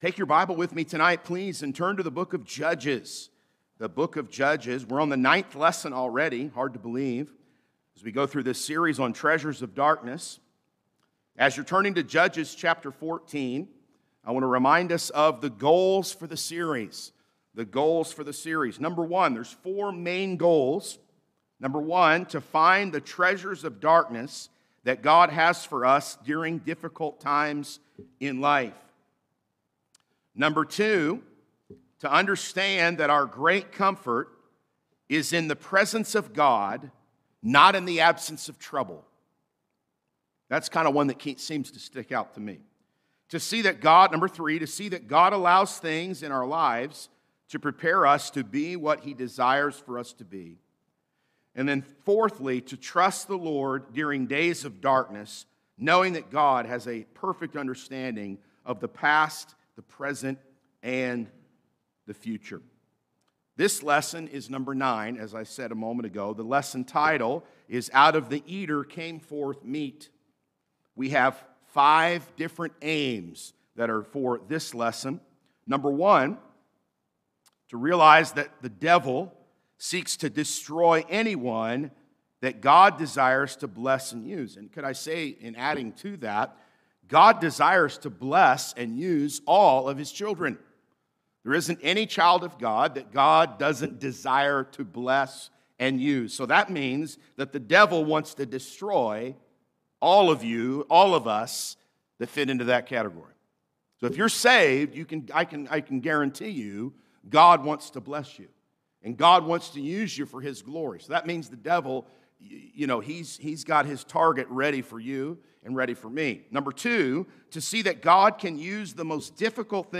Sermons | Anchor Baptist Church